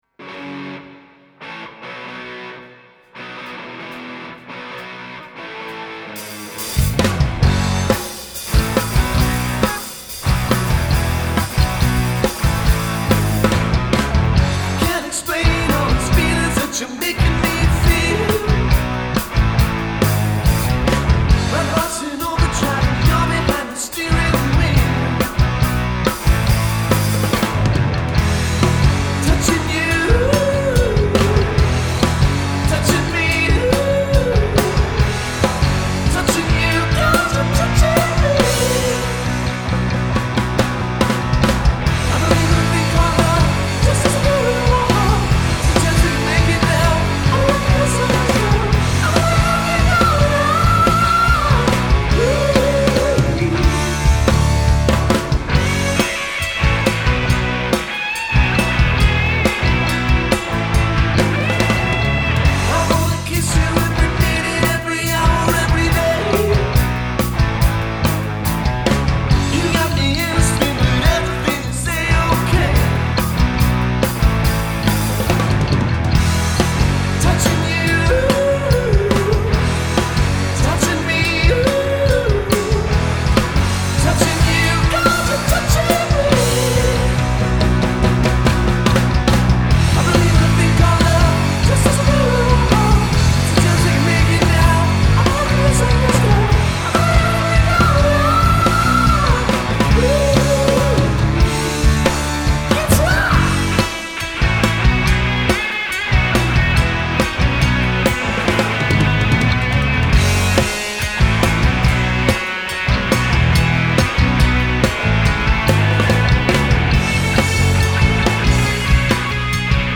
explosive power trio
classic blues/rock covers